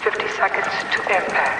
• Woman over PA - 50 seconds to impact.ogg
[woman-over-pa]-50-seconds-to-impact_mvk.wav